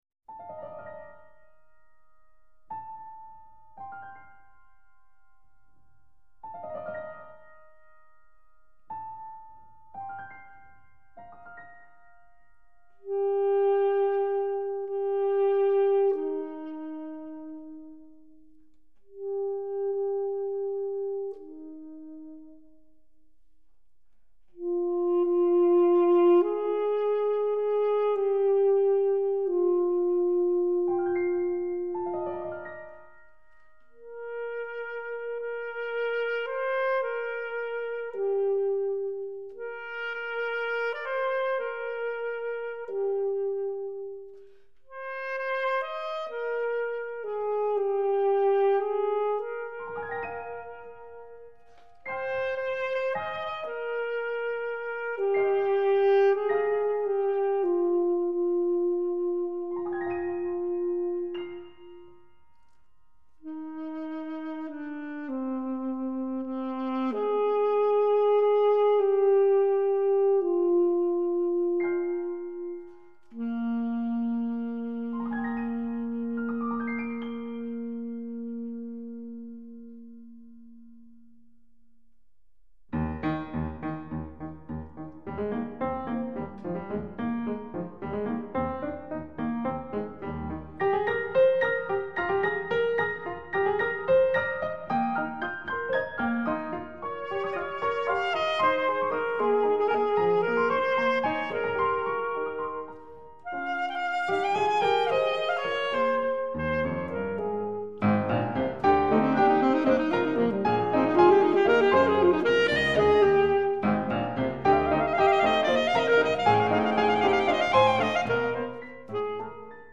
alto sax
piano
Tranquillo/vivace   –   Andante/gazioso
Moderato/animato Audio
* Premier performance